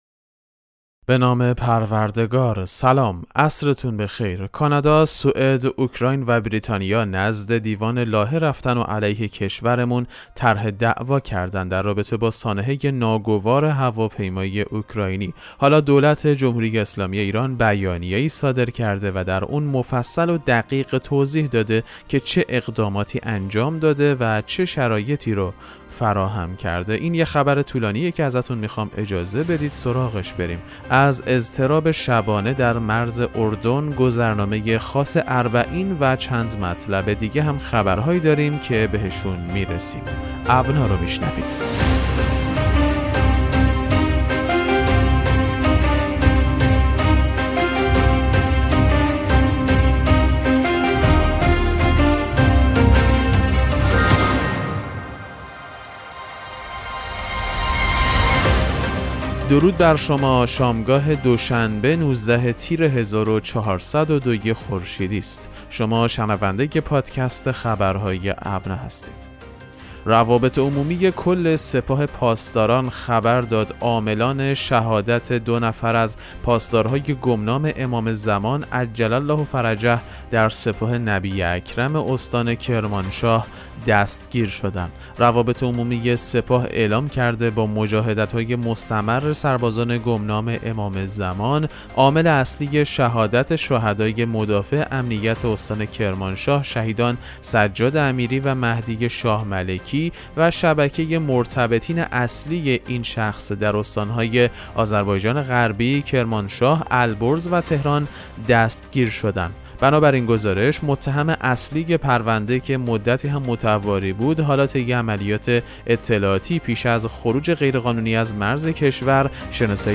پادکست مهم‌ترین اخبار ابنا فارسی ــ 19 تیر 1402